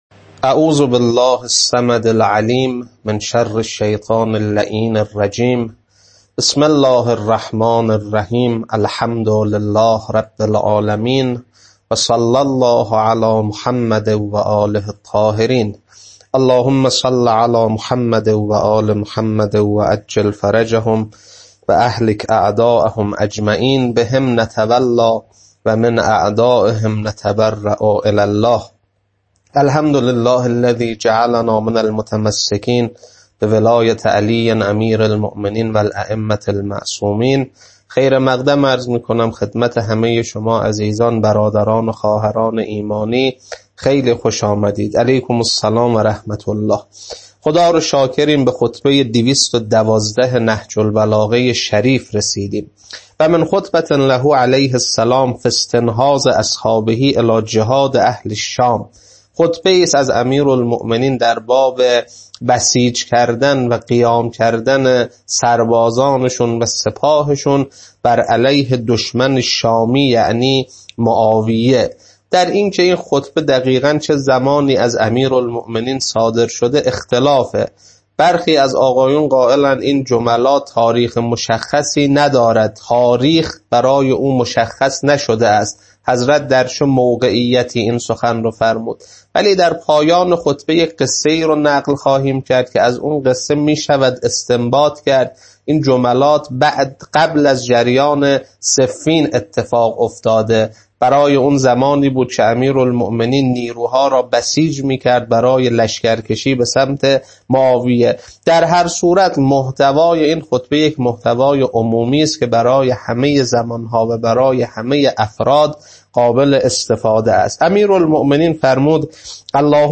خطبه 212.mp3